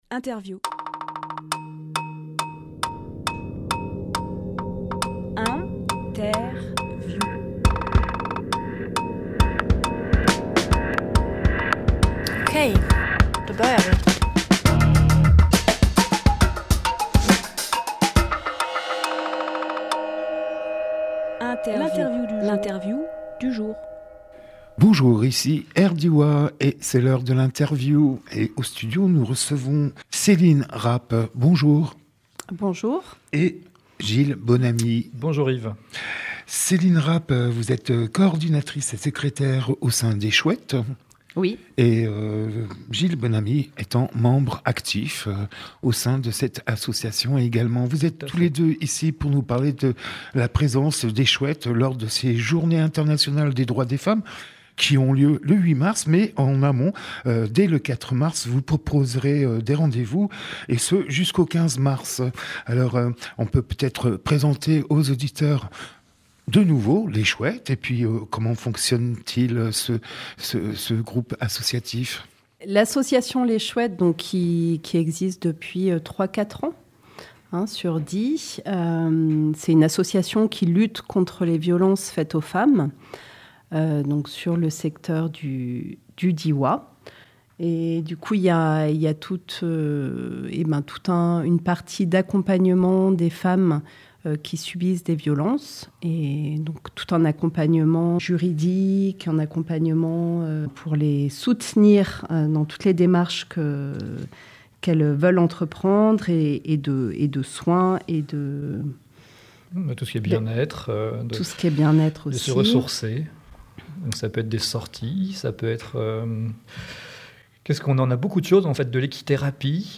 Emission - Interview Rendez-vous donnés par les Chouettes Publié le 27 février 2024 Partager sur…
Lieu : Studio RDWA